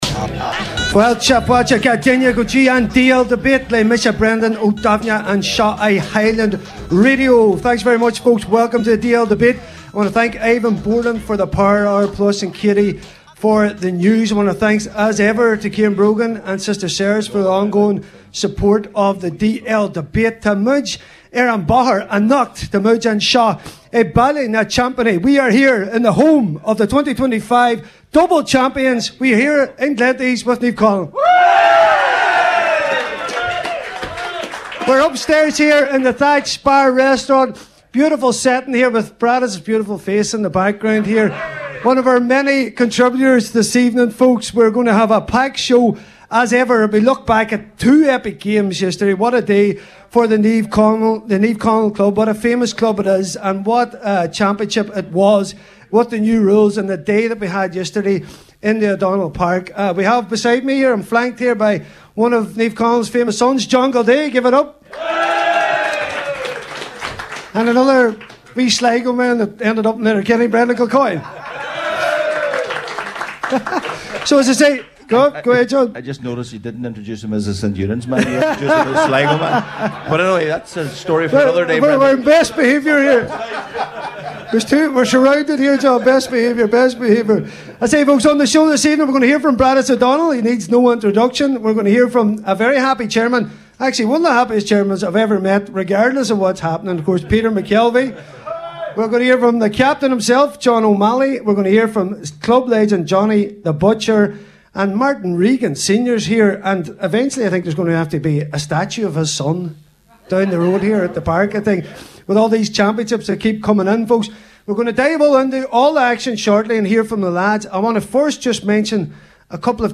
DL Debate – The Championship LIVE from Naomh Conaill